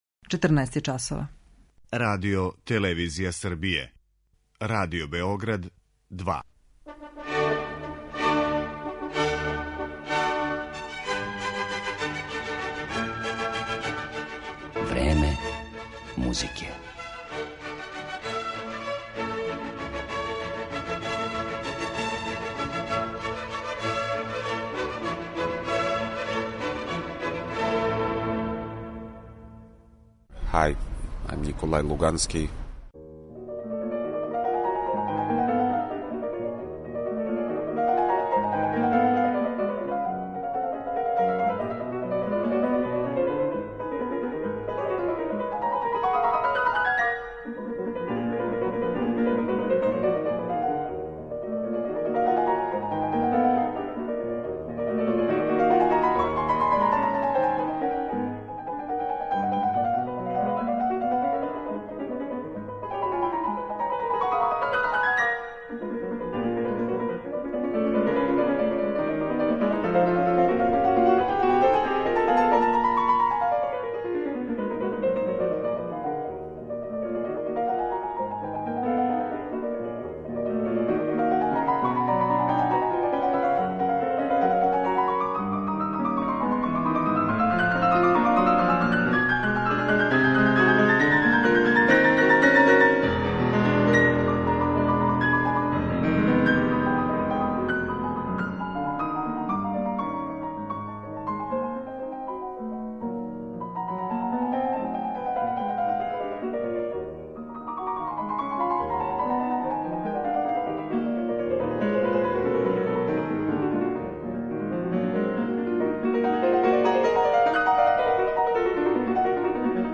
Сутра и прекосутра увече, у Коларчевој задужбини у Београду, наступиће један од највећих солиста данашњице, руски пијаниста Николај Лугански, који ће свирати са Београдском филхармонијом. Тим поводом, посвећена му је данашња емисија, у којој ћемо овог великог уметника представити кроз ексклузивни инстервју, а слушаћемо га и како изводи дела Рахмањинова, Шопена, Шумана, Бетовена и Прокофјева.